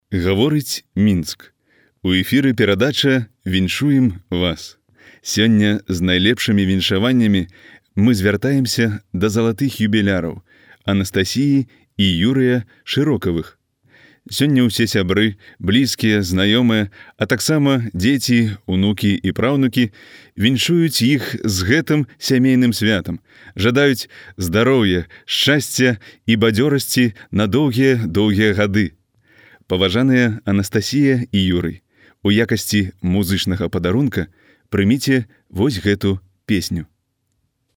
Belarusca Seslendirme
Erkek Ses